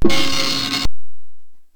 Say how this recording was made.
A little sound effect I like to call: Preooow! I recorded it from the Podstar Runner version of virus, so it is in fairly high-quality.